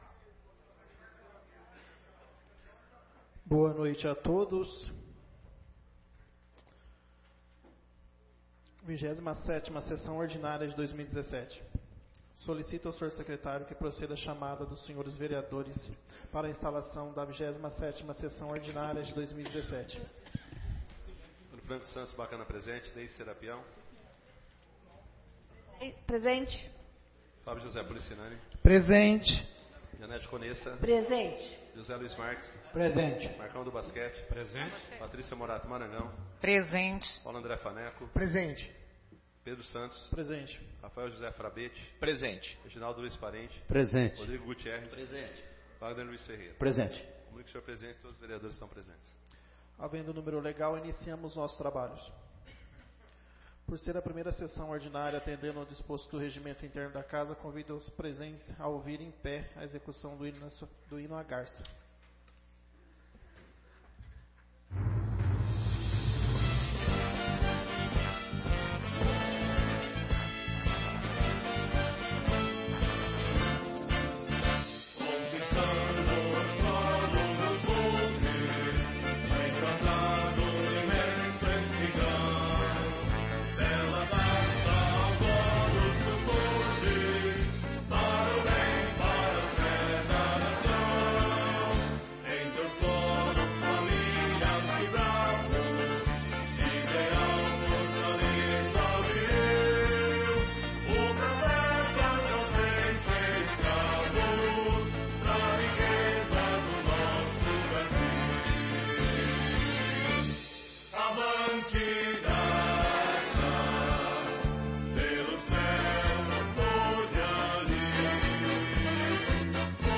27ª Sessão Ordinária de 2017